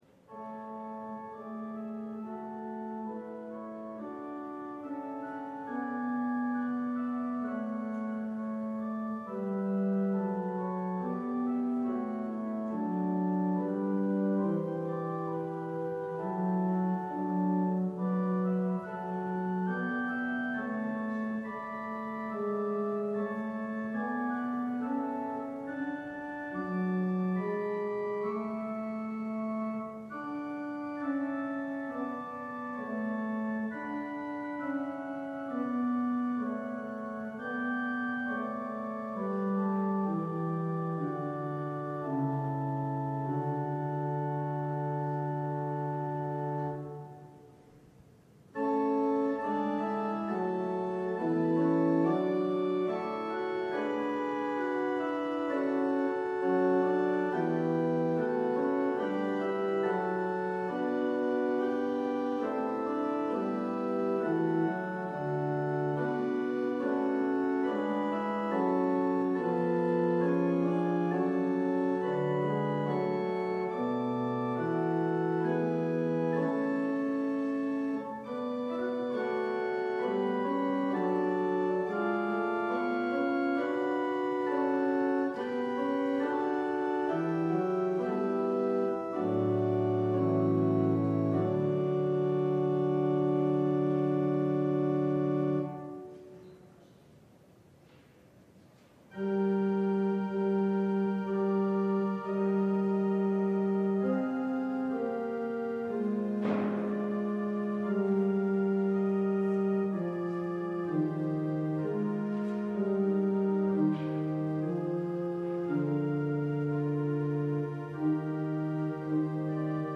LIVE Evening Worship Service - At Home With God
Congregational singing—of both traditional hymns and newer ones—is typically supported by our pipe organ. Vocal choirs, handbell choirs, small ensembles, instrumentalists, and vocal soloists provide additional music offerings.